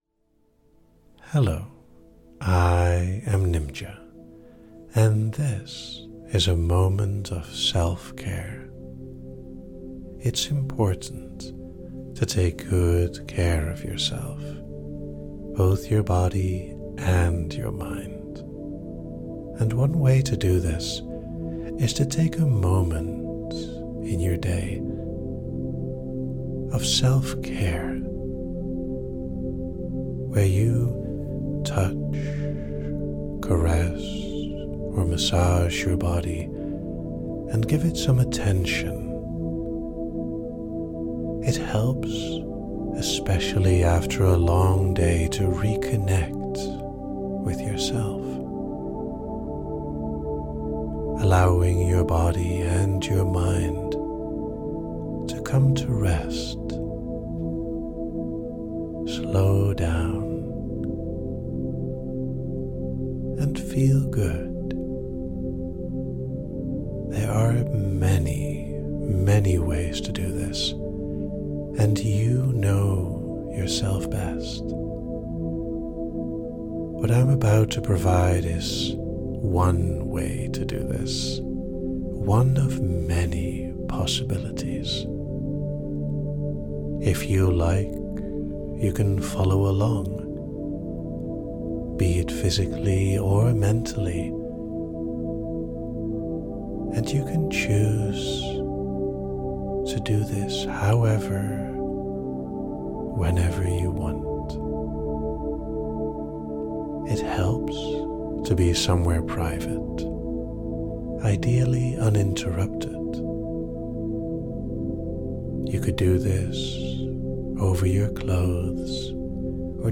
The listener is guided through a slow, mindful self-care routine intended to promote relaxation. The listener is instructed to begin by attending to their left leg, moving attention from the thigh down to the foot, before repeating the process on the right leg.